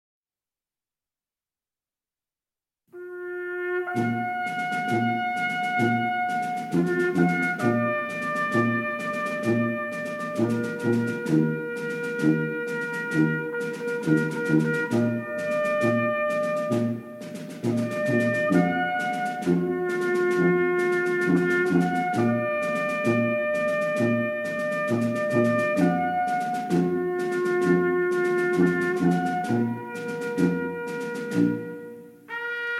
Clairon Sib
Clairon.mp3